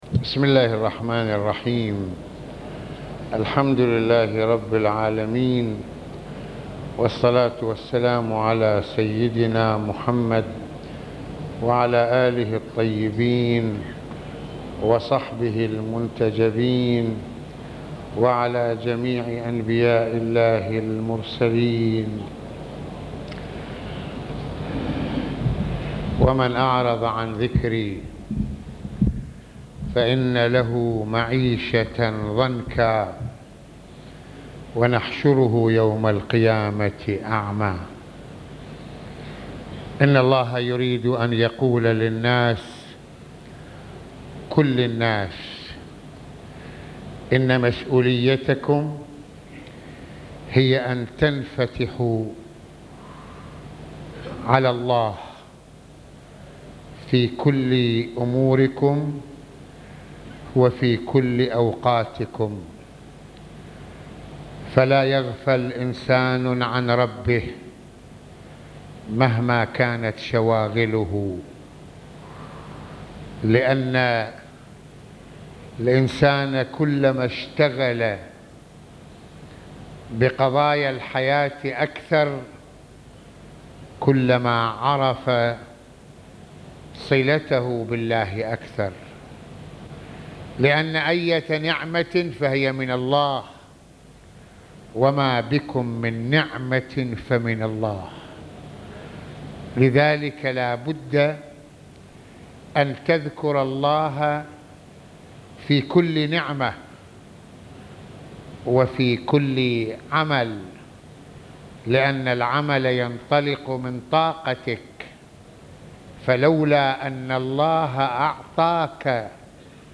- يتحدث العلامة المرجع السيّد محمّد حسين فضل الله(رض) في هذه المحاضرة عن دعوة الله الناس للإنفتاح على ذكره مهما كانت شواغلهم، وأن يستحضروه في كلّ نعمةٍ وعملٍ يقومون به، ويقبلوا عليه بكلّ أوضاعهم. أمّا المعرضون عن ذكر الله تعالى فهم الخاسرون في دنياهم وآخرتهم، فالمؤمن بالله واسع الأفق واثق الخطى، والمعرض عن الله ضيق الصدر والأفق، وميت الروح والعقل...